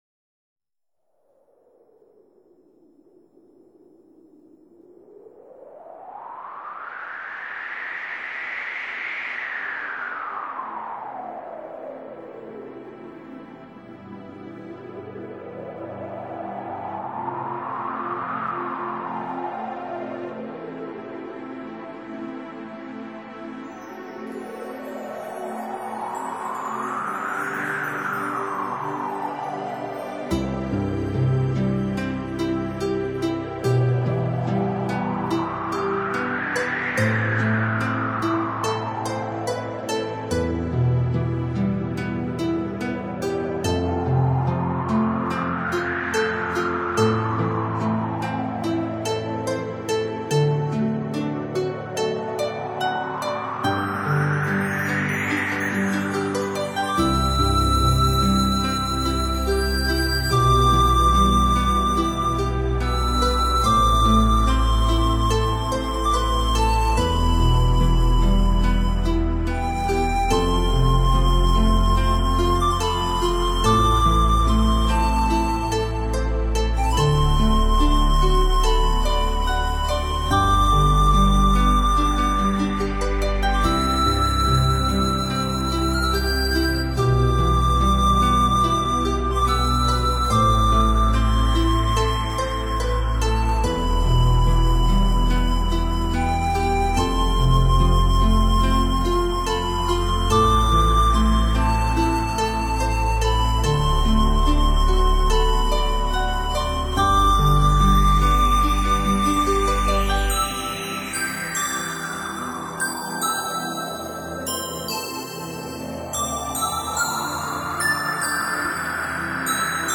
很多是电脑程序编曲